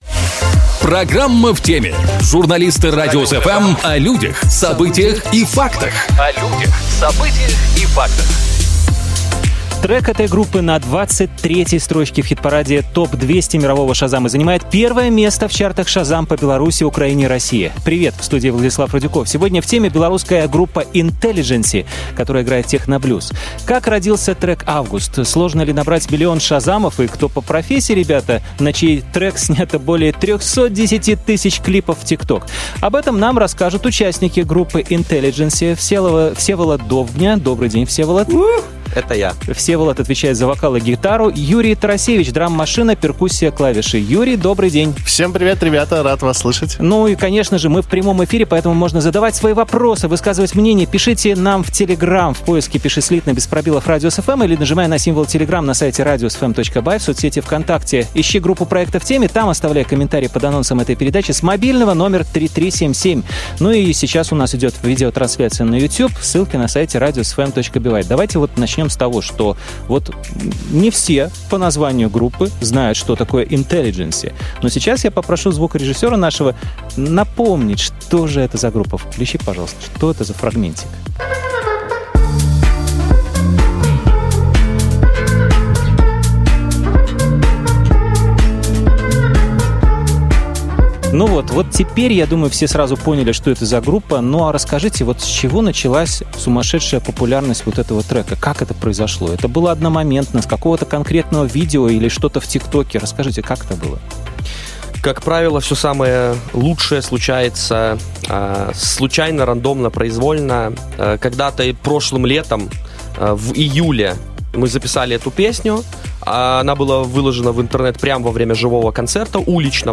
Сегодня "В теме" - белорусская группа INTELLIGENCY, которая играет техноблюз.